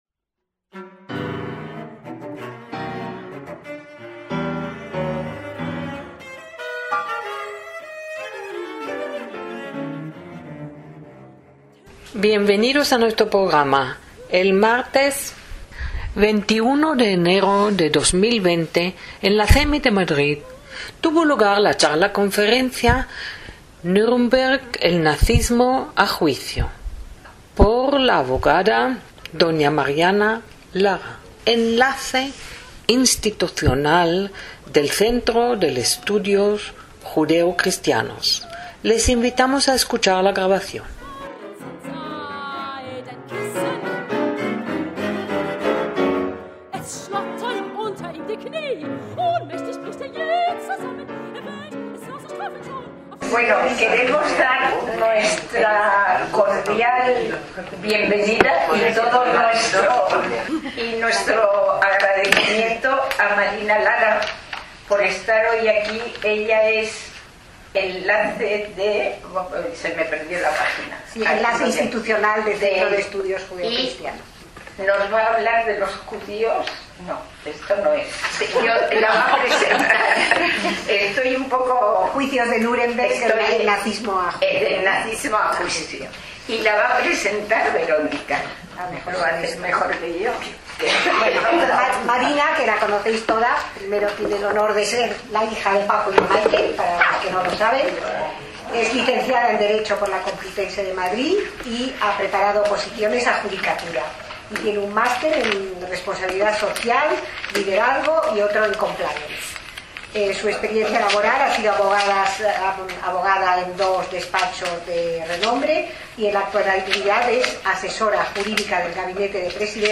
(CEMI, Madrid, 21/1/2020)